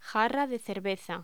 Locución: Jarra de cerveza
voz